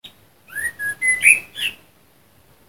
Het klonk als de beginregels van de Flippertune.
De eerste vier noten.
Vanaf het dak.
flipper_merel_fragment.mp3